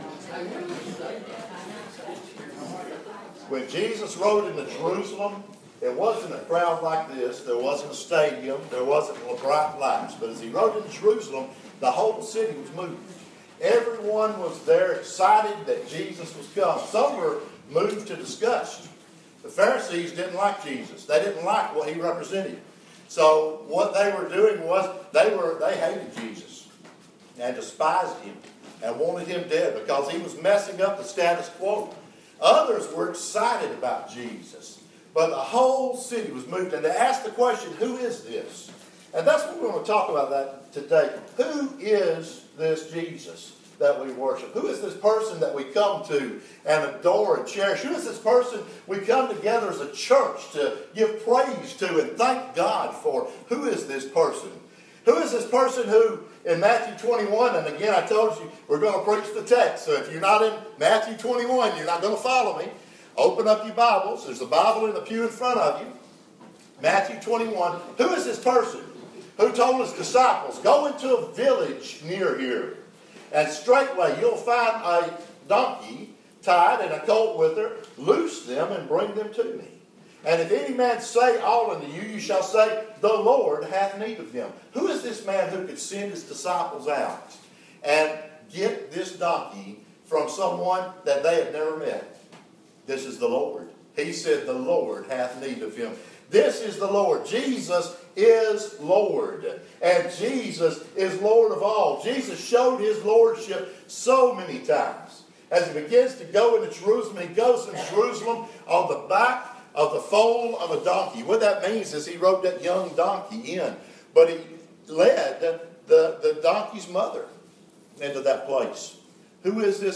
Preached at Riverview Baptist 798 Santa Fe Pike Columbia Tn on June 18, 2017